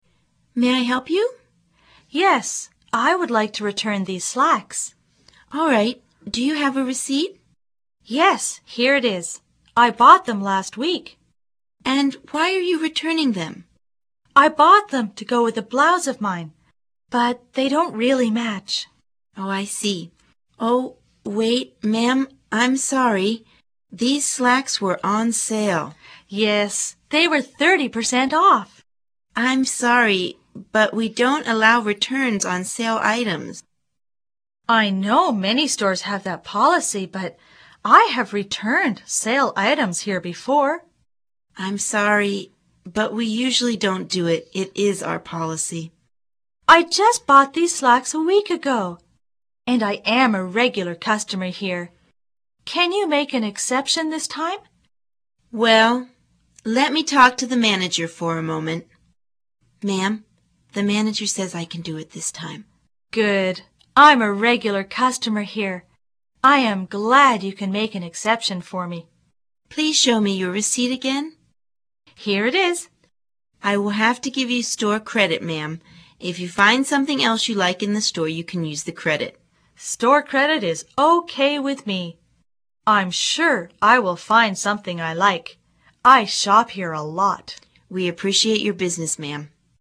购物英语对话 第7讲:退货